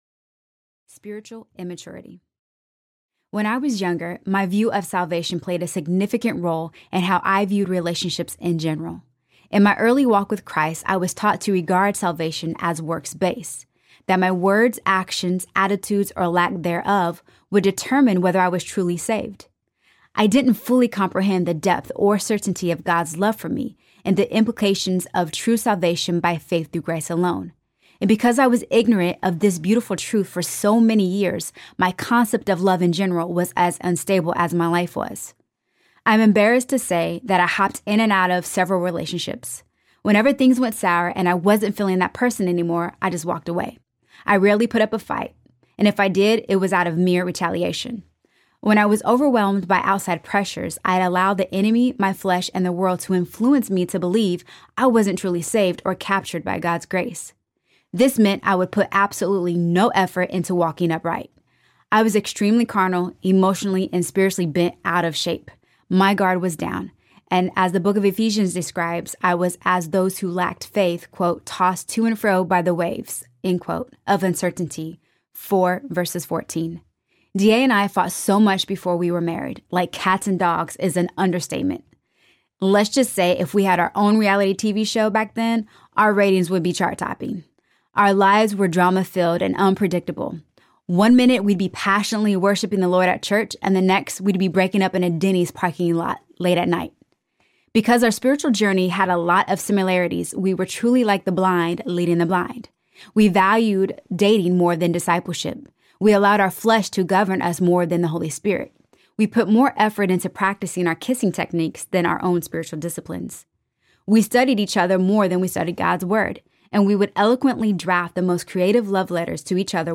Enter the Ring Audiobook
5.48 Hrs. – Unabridged